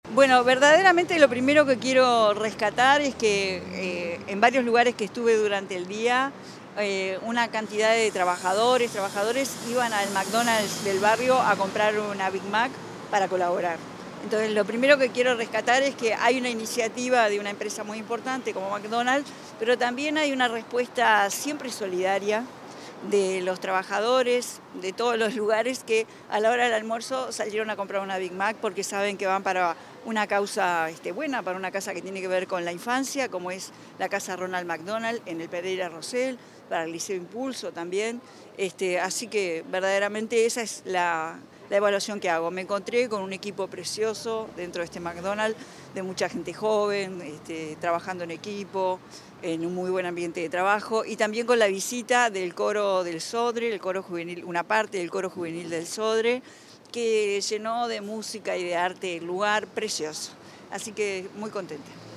Declaraciones de la presidenta de la República en ejercicio, Carolina Cosse
Declaraciones de la presidenta de la República en ejercicio, Carolina Cosse 17/10/2025 Compartir Facebook X Copiar enlace WhatsApp LinkedIn Tras asistir a la jornada solidaria de McDonald’s, a beneficio de la Asociación Casa Ronald McDonald y de los centros educativos Impulso, la presidenta en ejercicio, Carolina Cosse, dialogó con los medios informativos.